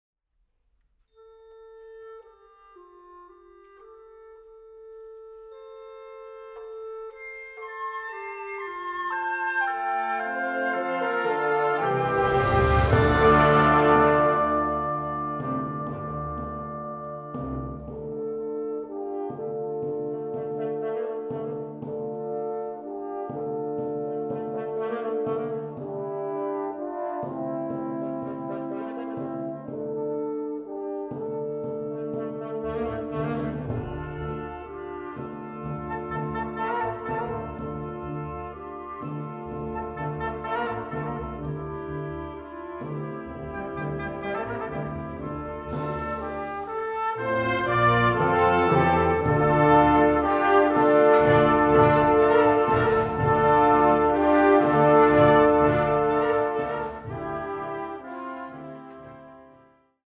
Musik für sinfonisches Blasorchester
Sinfonisches Blasorchester